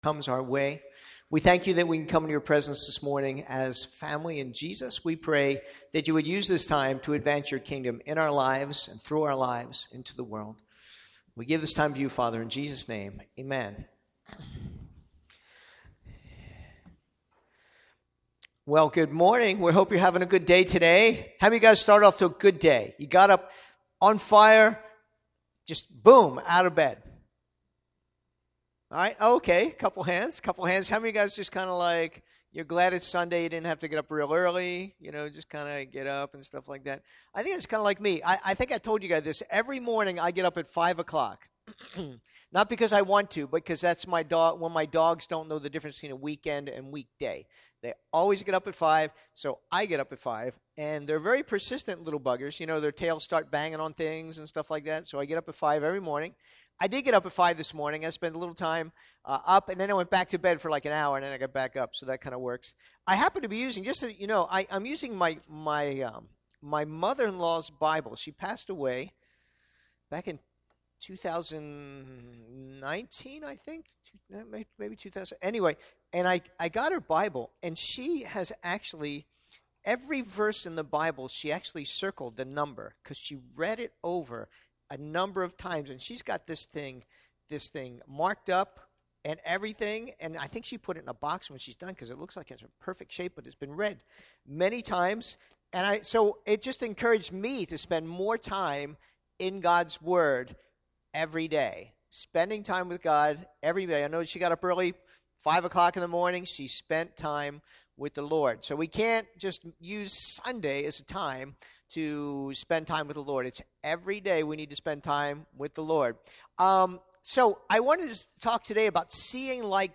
Past Sermons - Chinese Baptist Church of Miami